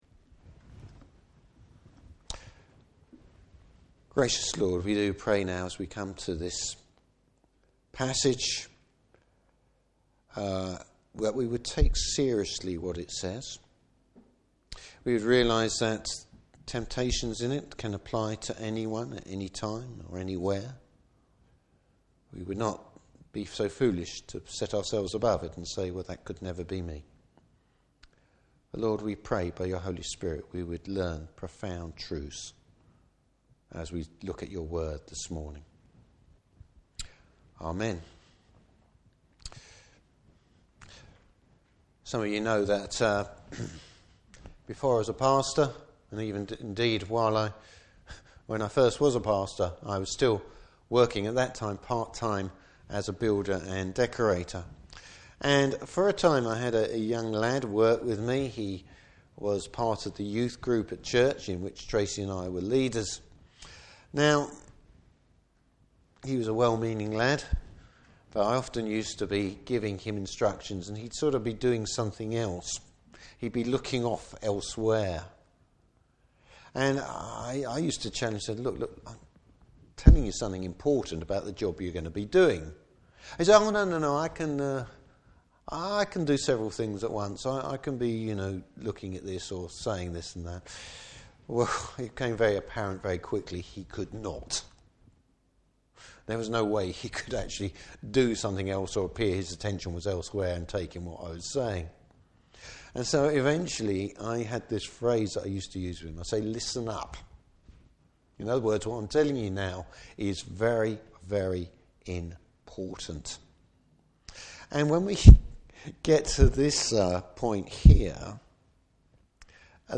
Service Type: Morning Service Lessons in steering clear of big trouble!